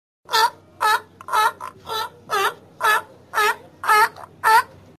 Тюлень громко кричит